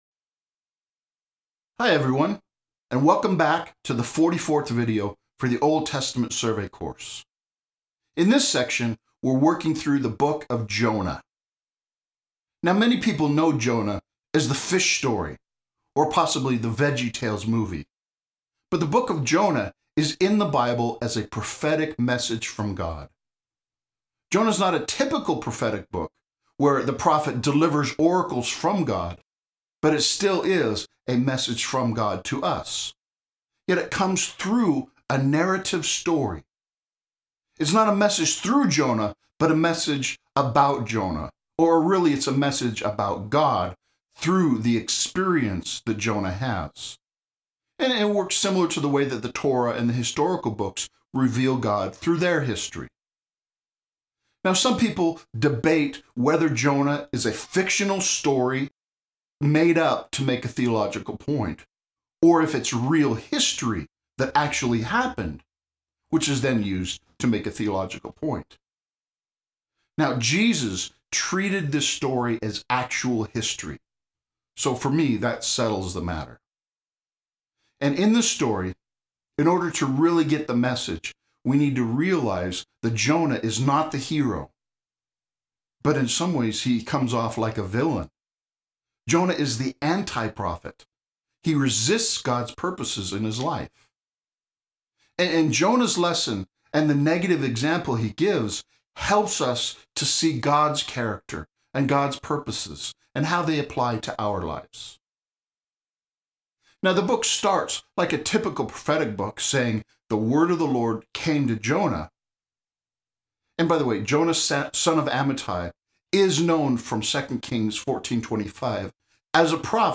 The second section has a survey video lecture covering the entire book.